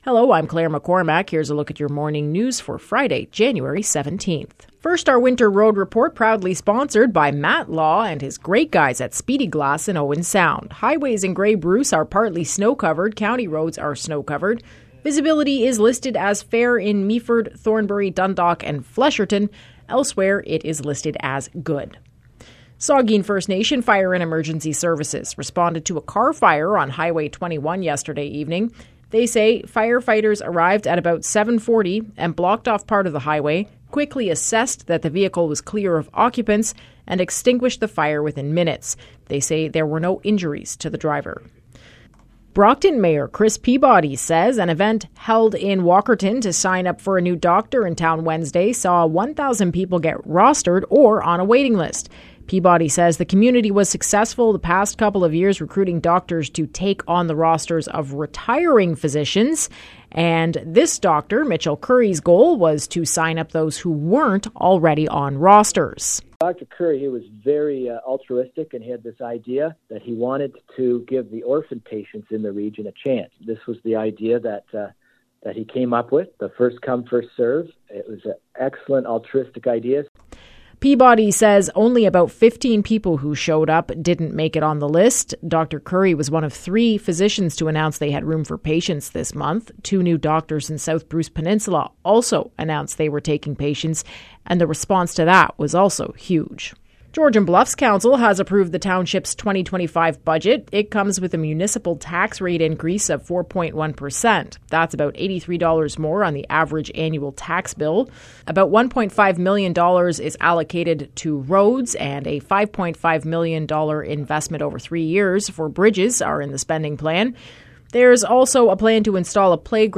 Morning News – Friday, January 17
web-news-jan-17-radio.mp3